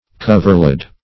Search Result for " coverlid" : The Collaborative International Dictionary of English v.0.48: Coverlid \Cov"er*lid\ (-l[i^]d), n. A coverlet.